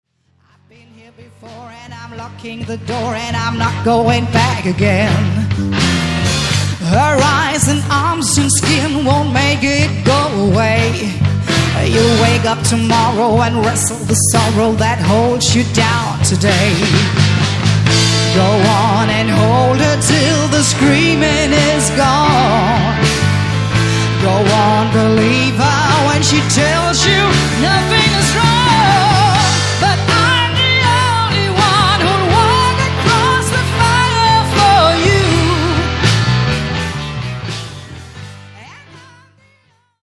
• Coverband
• Rockband